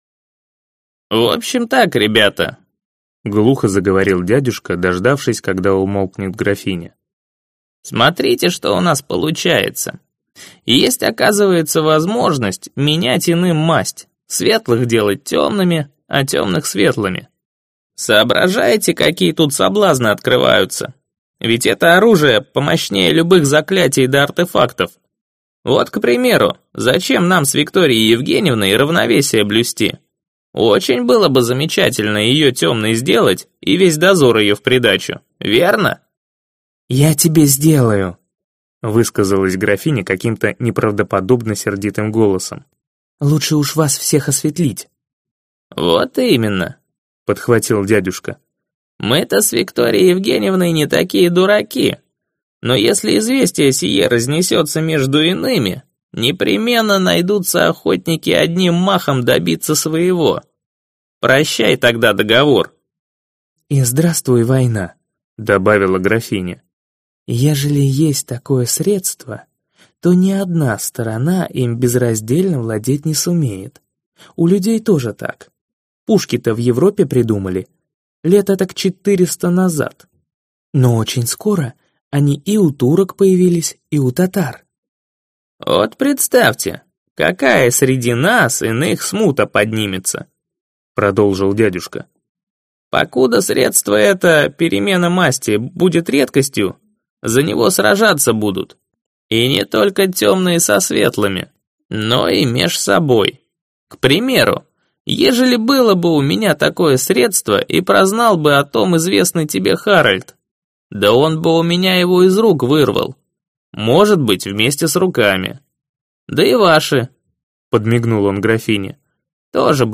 Аудиокнига Масть - купить, скачать и слушать онлайн | КнигоПоиск